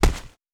Foley Sports / Soccer / Gloves Hit Powerful.wav
Gloves Hit Powerful.wav